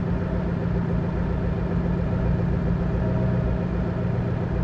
rr3-assets/files/.depot/audio/Vehicles/ttv8_03/ttv8_03_idle.wav